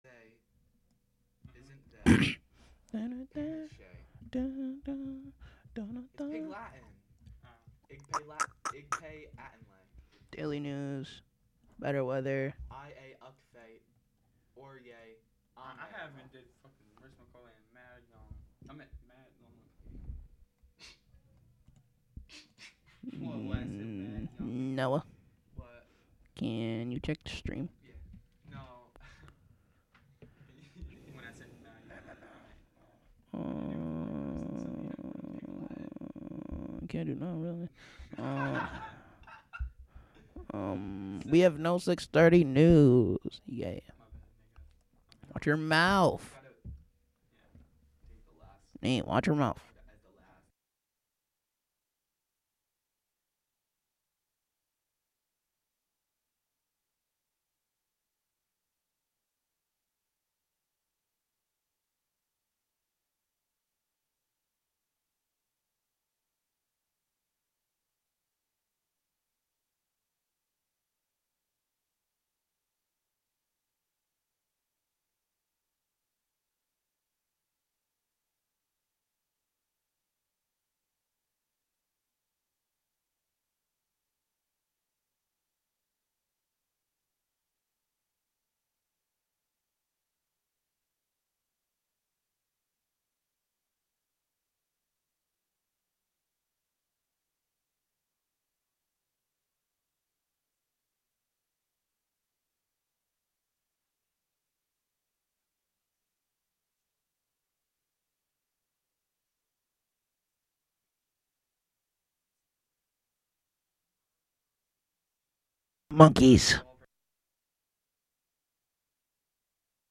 Live from the Catskill Clubhouse.